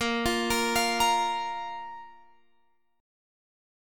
A#5 chord {18 20 20 x 18 18} chord